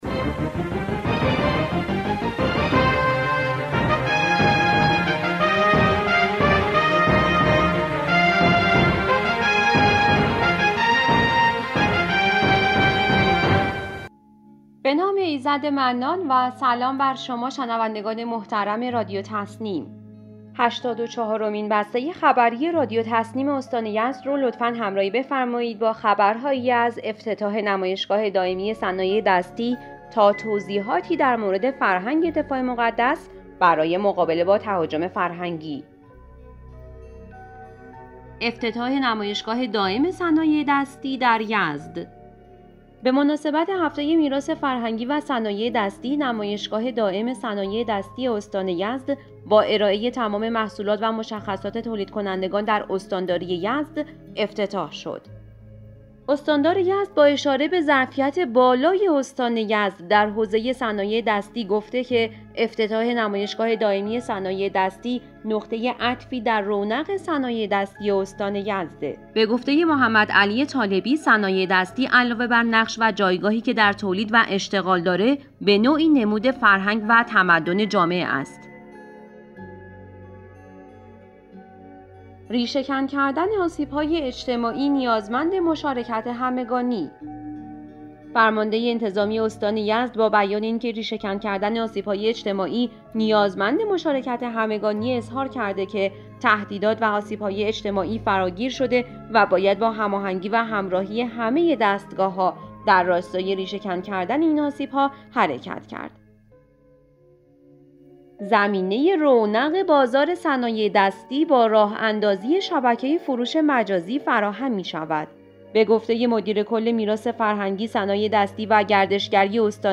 به گزارش خبرگزاری تسنیم از یزد, هشتاد و چهارمین بسته خبری رادیو تسنیم استان یزد با خبرهایی از افتتاح نمایشگاه صنایع دستی, اظهارات استاندار در مورد صنایع دستی و میراث فرهنگی, توصیه فرمانده انتظامی استان در مورد ریشه کن کردن آسیب‌های اجتماعی, فراهم شدن زمینه رونق صنایع دستی با راه‌اندازی شبکه فروش مجازی و توصیه معاون فرهنگی سپاه الغدیر در مورد فرهنگ دفاع مقدس منتشر شد.